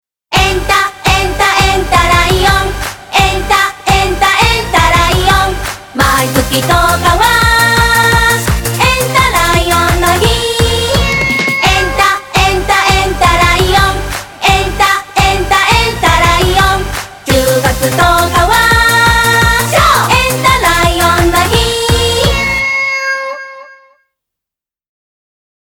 テーマ曲&ダンスはこれ♪